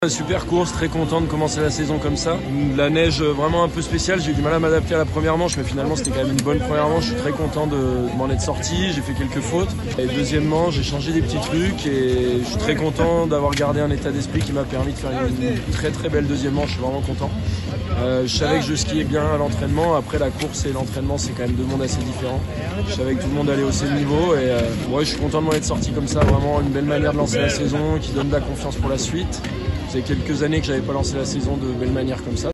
Clément Noel au micro de FFS TV à l’issue de la course : Télécharger le podcast Partager :